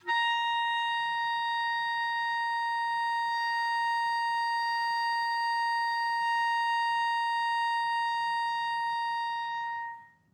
DCClar_susLong_A#4_v2_rr1_sum.wav